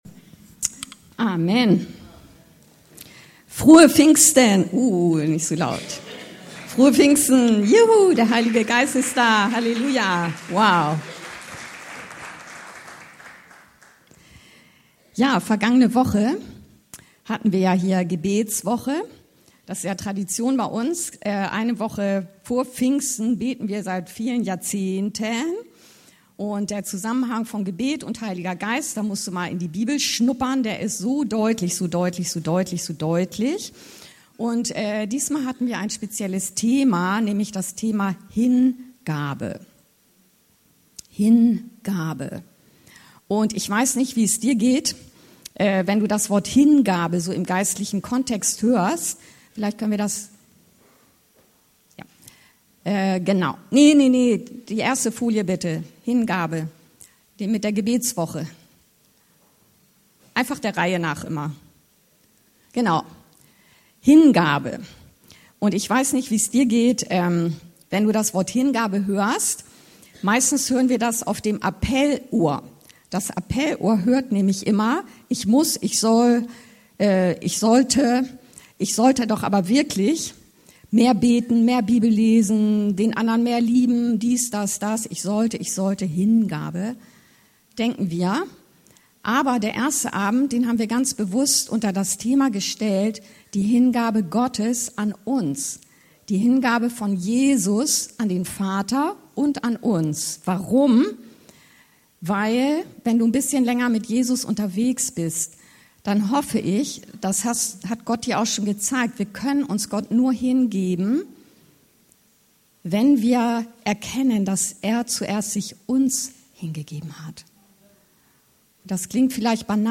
Hingabe - Pfingstgottesdienst 2024 ~ Anskar-Kirche Hamburg- Predigten Podcast